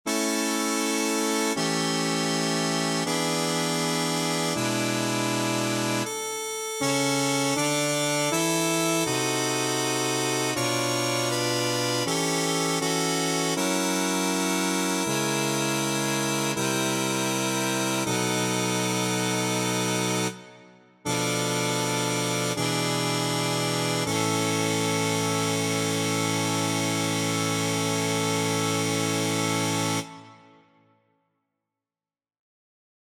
How many parts: 4
Type: Barbershop
All Parts mix: